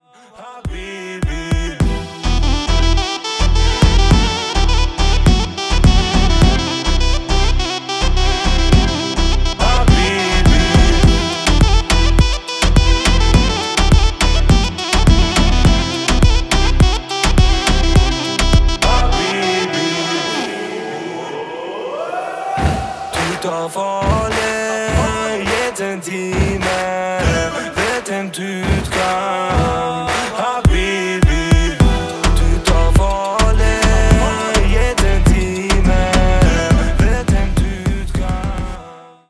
Marimba Remix ,TikTok